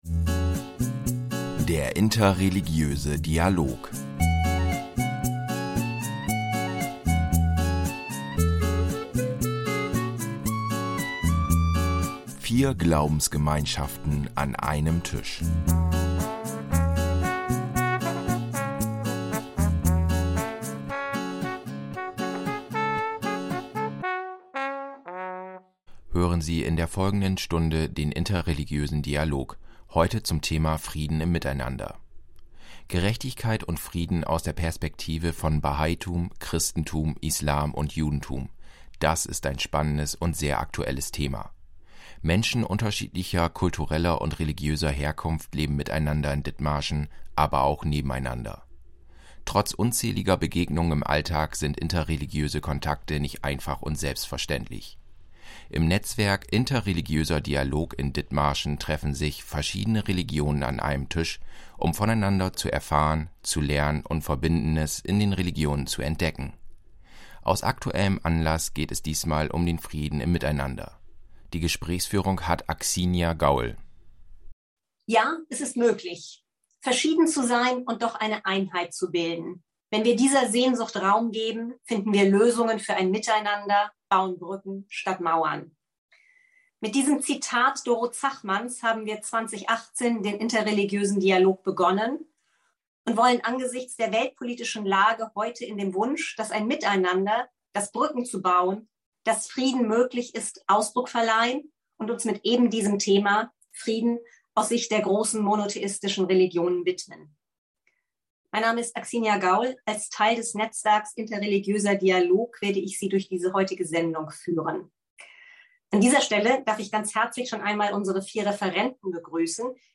Beschreibung vor 7 Monaten In einer digitalen dialogischen Begegnung zum Thema „Frieden im Miteinander“ tauschen sich Referent*innen von Judentum, Christentum, Islam und Bahaitum miteinander aus, was Frieden und Gerechtigkeit im Hinblick der Religionen bedeuten. Es wird dargelegt, wie der Umgang mit dem anderen, mit anderen Religionen und mit Nichtgläubigen gedacht ist und welche Konfliktlösungsstrategien in den einzelnen Religionen stecken.